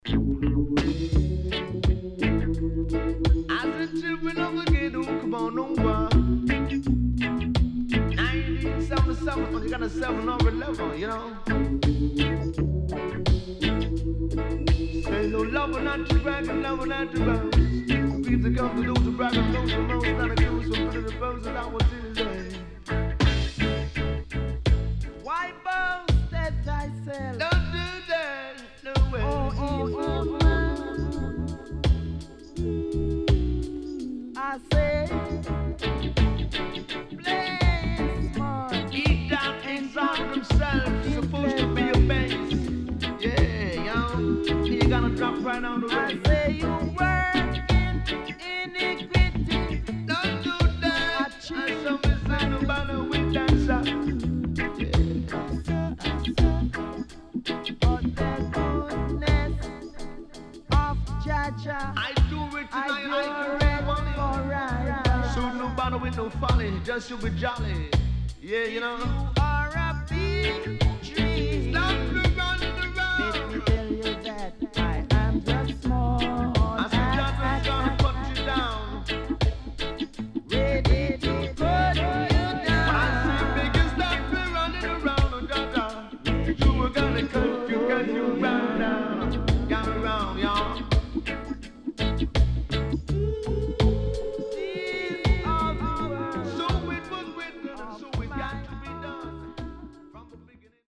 往年の名曲の数々にTalk Overした傑作盤
SIDE A:少しチリノイズ入りますが良好です。